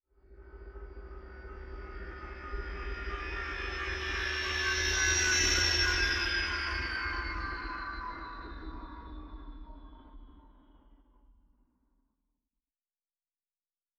Distant Ship Pass By 2_3.wav